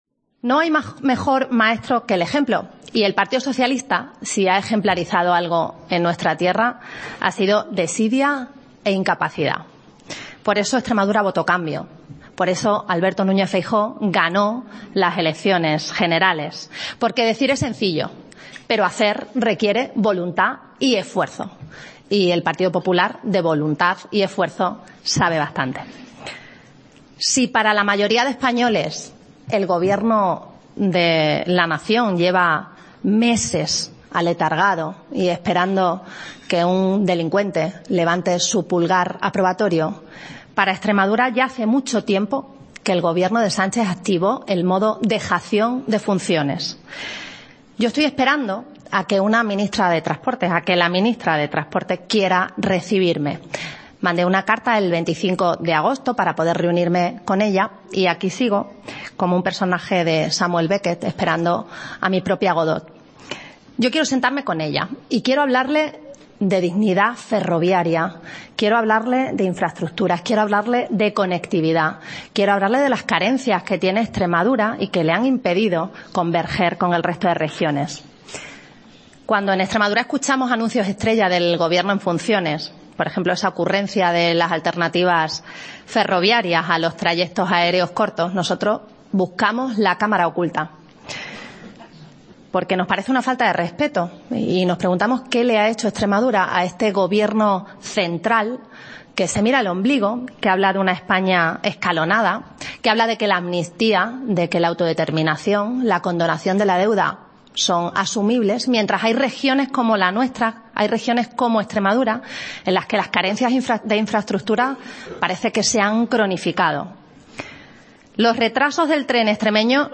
Así lo ha afirmado en un Desayuno Informativo organizado por Europa Press, en el que ha acusado al Ejecutivo central de estar "en dejación de funciones", criticando a su vez que lleva esperando "desde el 25 de agosto" a que la titular de Transportes, Movilidad y Agenda Urbana se reúna con ella.